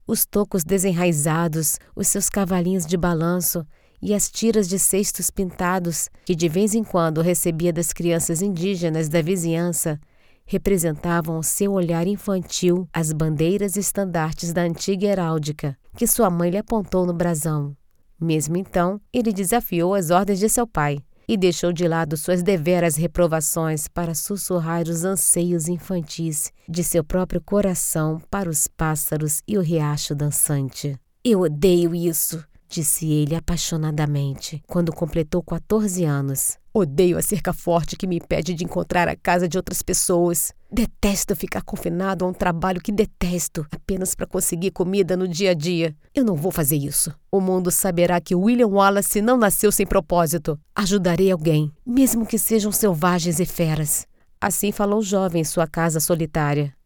I have a natural and versatile voice, free from vices or exaggerations, with neutral Brazilian Portuguese suitable for different age groups and styles.
I have an isolated home studio treated with professional equipment: AT2020 mic and Focusrite Scarlett card.
Sprechprobe: Sonstiges (Muttersprache):